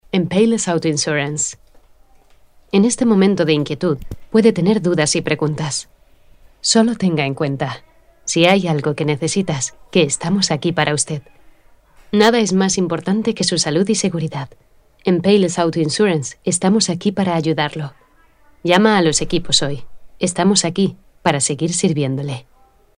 女西106 西班牙语女声 略成熟稳重 低沉|激情激昂|大气浑厚磁性|沉稳|娓娓道来|科技感|积极向上|时尚活力|神秘性感|调性走心|亲切甜美|感人煽情|素人